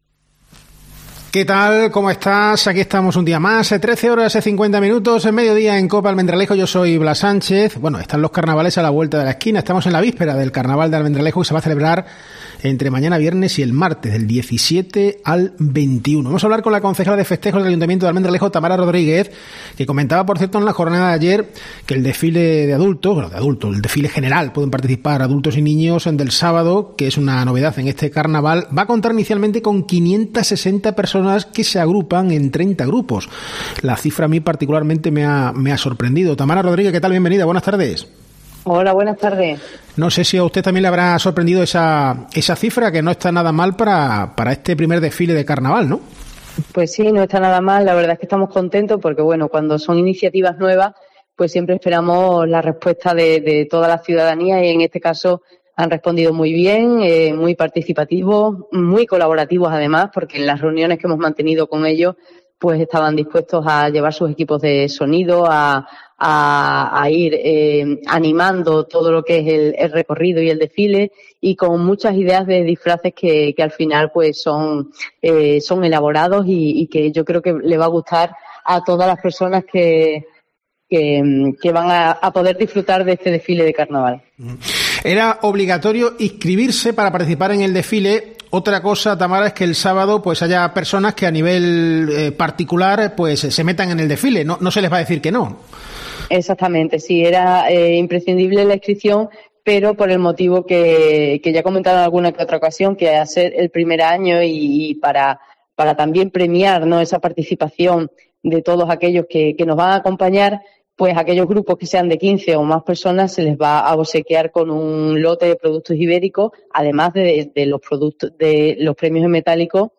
Así lo ha anunciado la concejala Tamara Rodríguez, a la que hemos entrevistado en COPE.